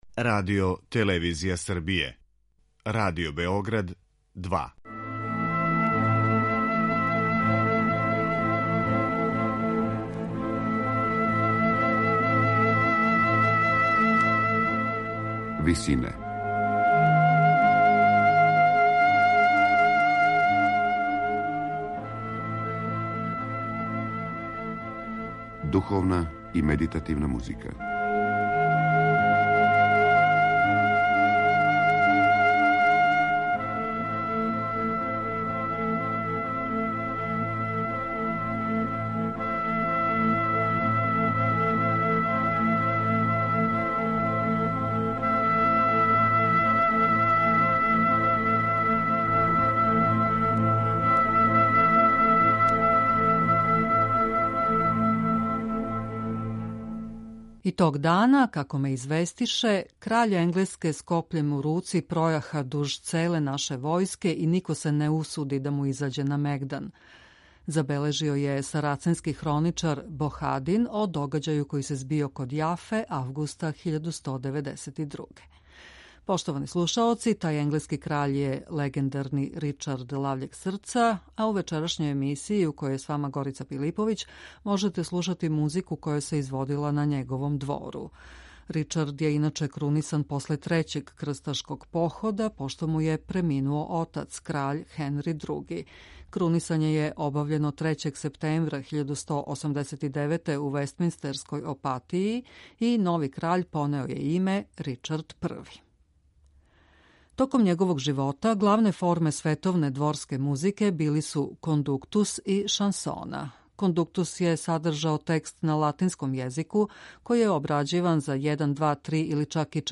Избор из овог репертоара слушаћемо у извођењу ансамбла „Готички гласови", који предводи Кристофер Пејџ.
Током живота Ричарда I, главне форме световне дворске музике били су кондуктус и шансона.